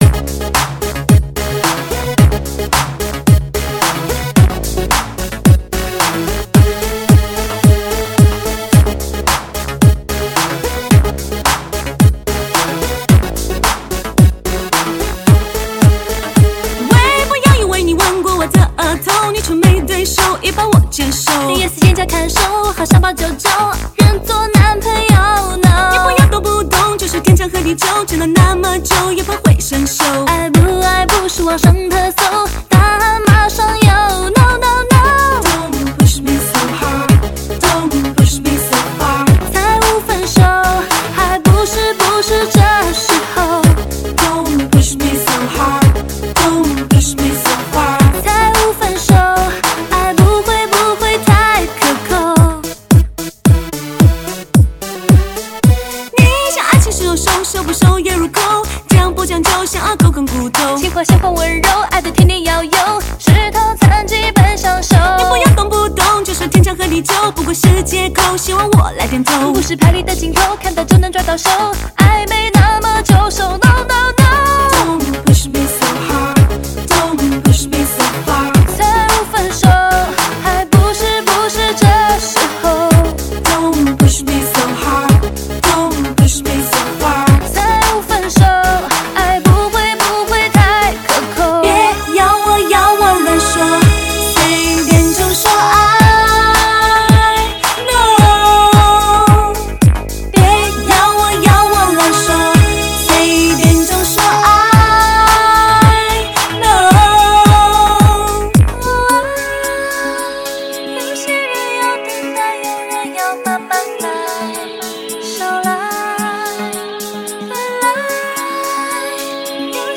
吉他
激情的歌舞、绚丽的音乐、激每一分钟都激动人心。